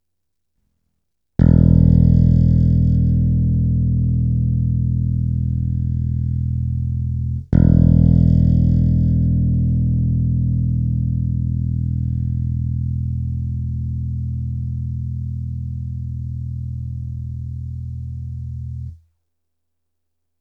No ono to zas tak nepatrný není.
Drnk
Ale takhle mě to štvě, je to slyšet i na sucho.